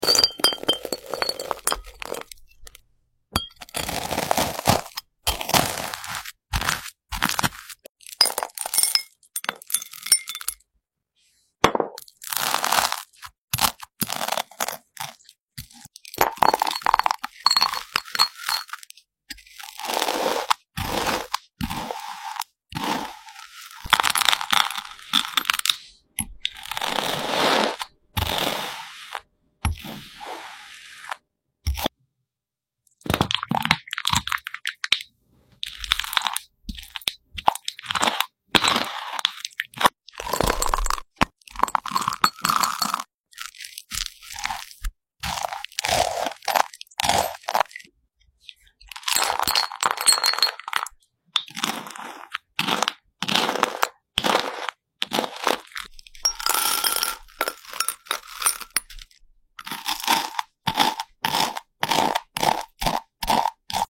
Spreading strawberries on toast ASMR